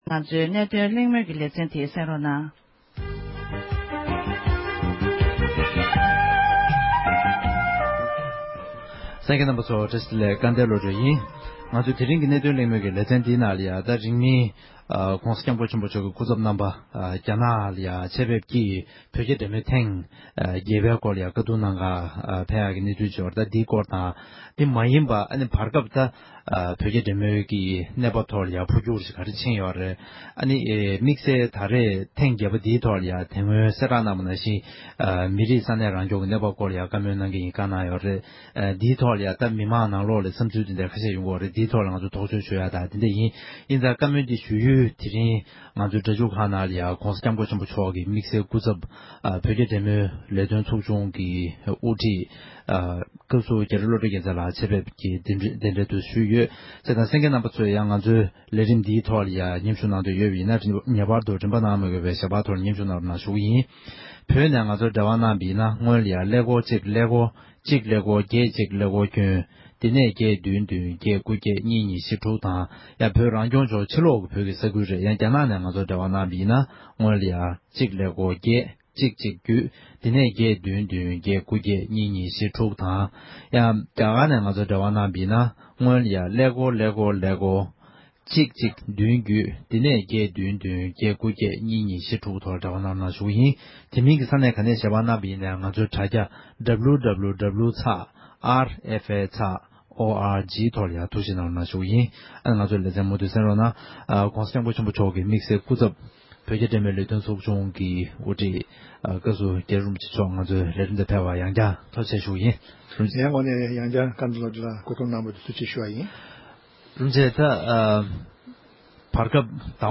དམིགས་བསལ་སྐུ་ཚབ་ལྷན་གླེང་བ།